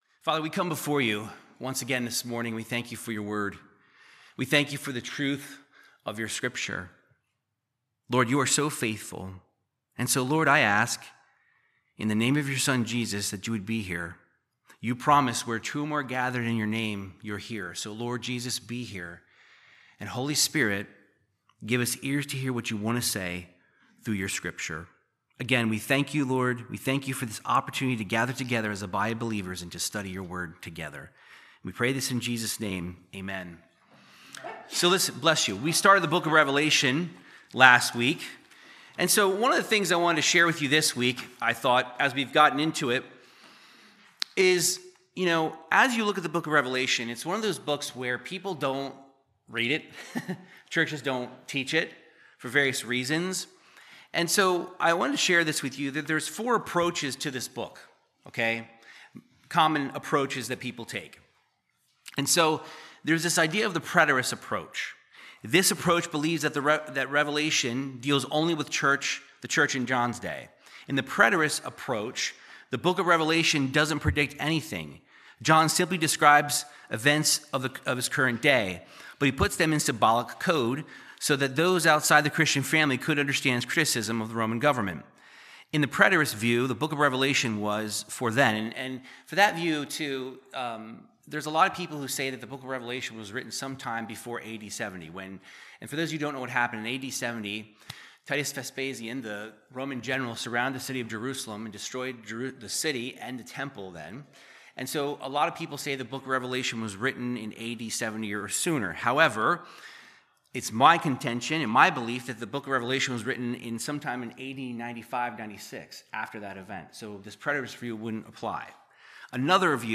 Verse by verse Bible teaching through Revelation 1:9-20